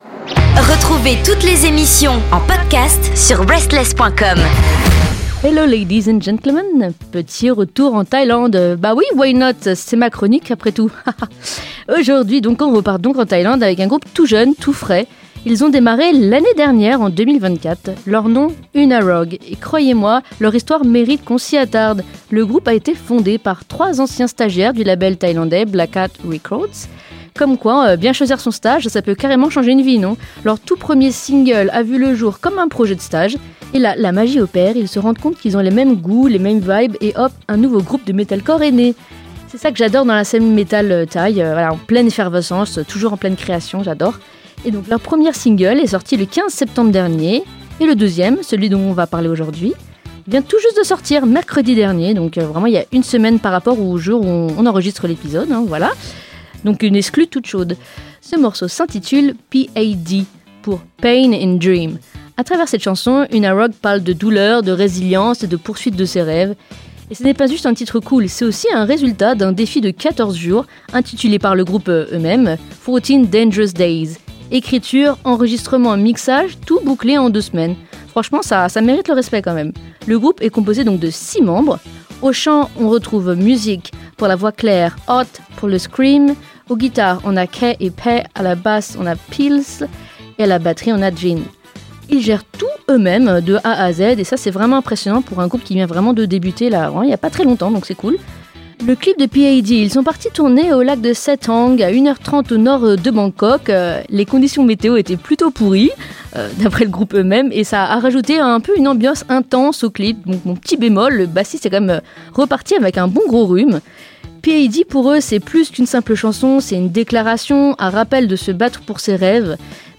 Direction la scène metalcore avec UNAROGUE, une toute jeune formation pleine d’énergie et de talent.